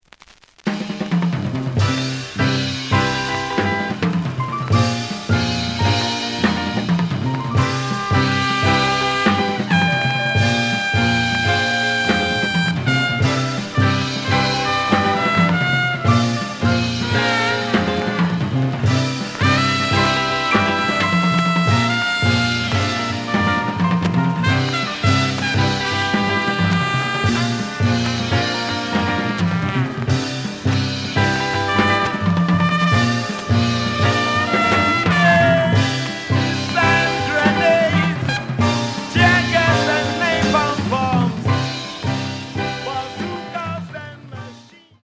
The audio sample is recorded from the actual item.
●Genre: Latin Jazz
Noticeable periodic noise on first half of A side.